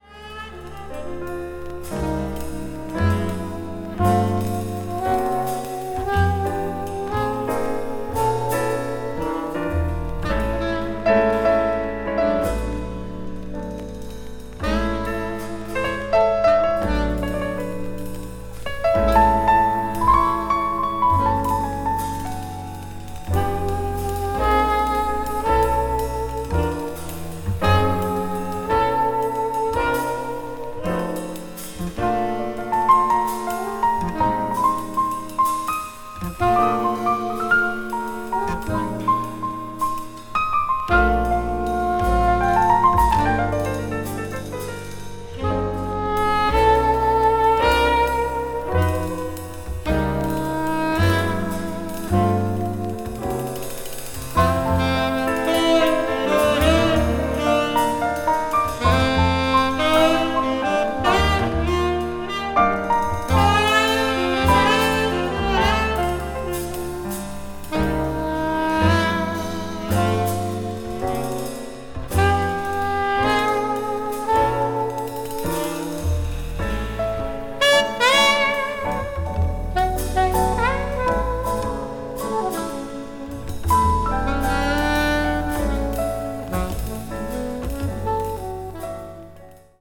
contemporary jazz   ethnic jazz   free jazz   spritual jazz